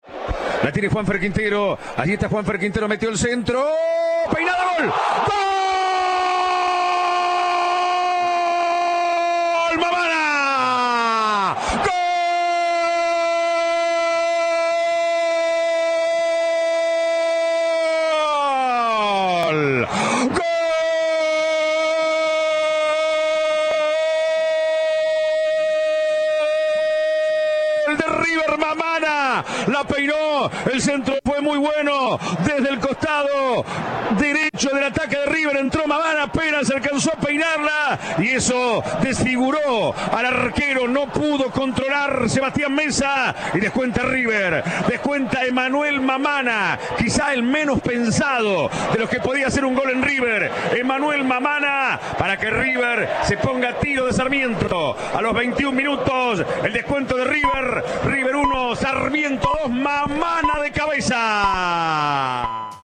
Reviví las narraciones de los goles en la noche de Núñez